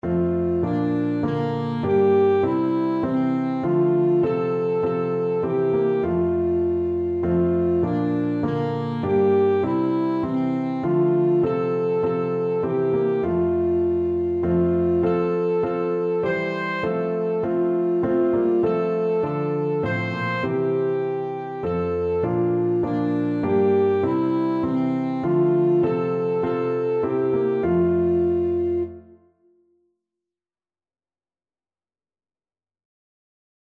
Alto Saxophone version
Alto Saxophone
3/4 (View more 3/4 Music)
Classical (View more Classical Saxophone Music)